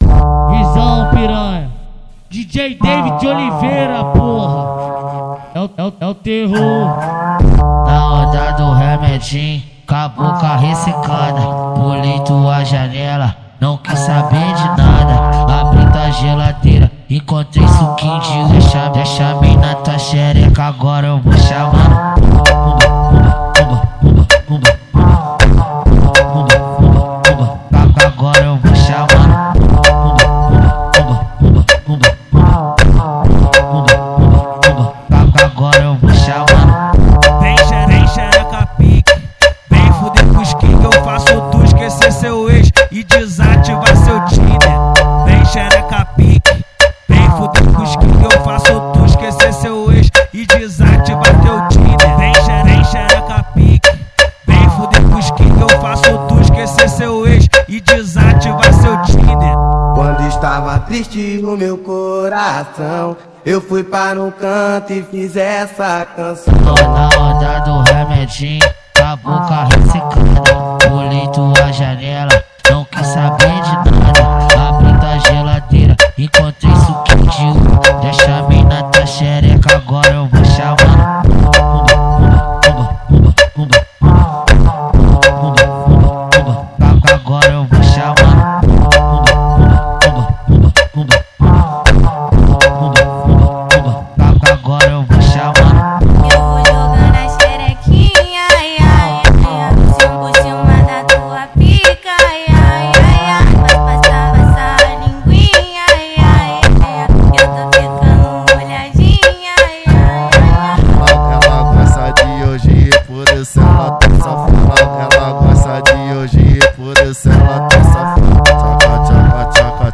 2024-12-20 23:51:36 Gênero: Phonk Views